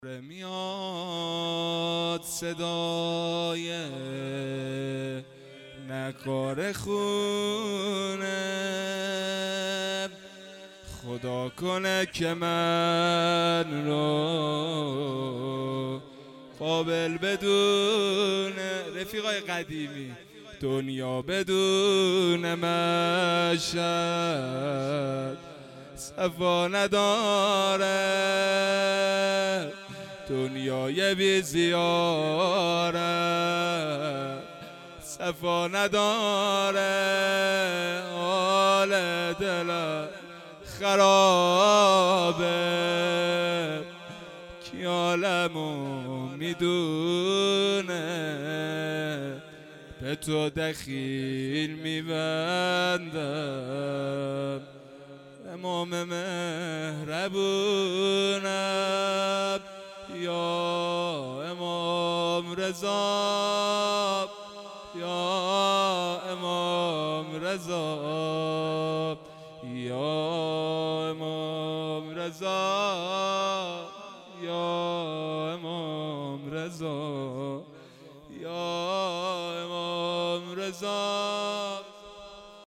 مناجات - داره میاد صدای نقاره خونه
اصوات مراسم فاطمیه اول 98